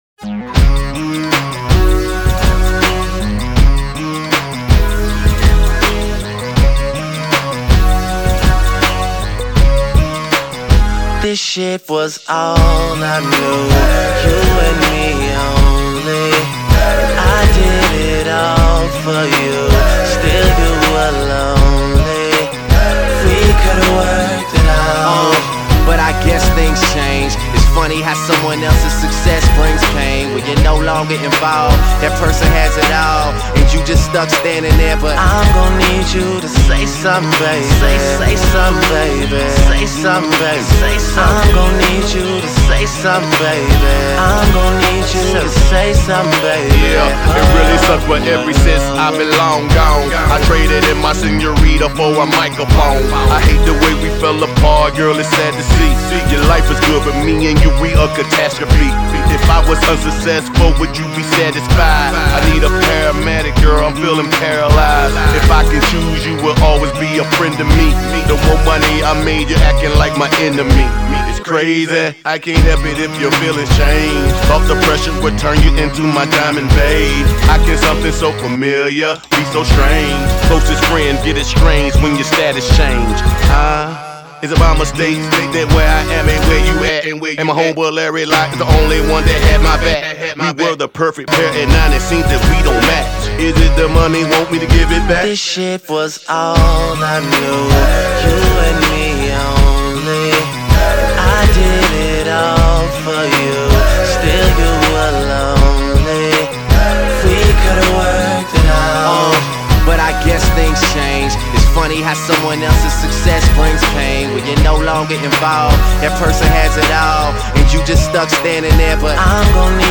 Категория: Танцевальная